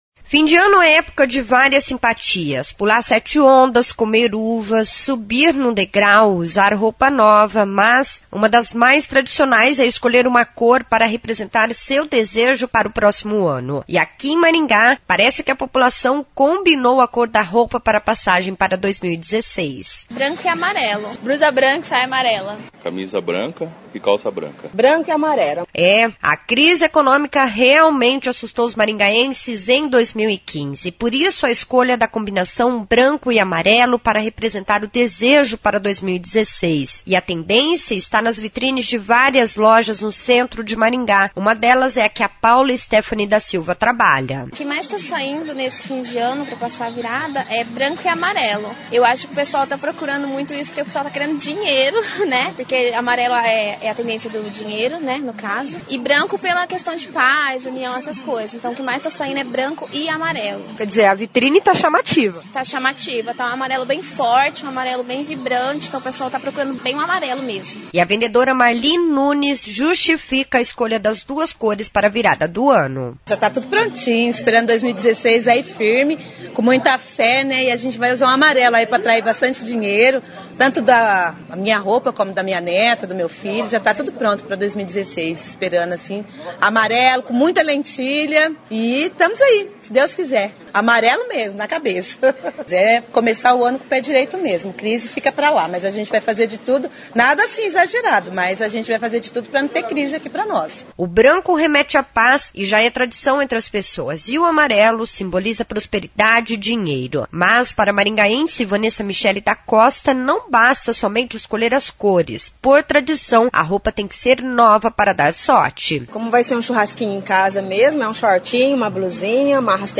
População quer começar 2016 com paz e dinheiro no bolso. A reportagem da CBN foi às ruas ouvir os maringaenses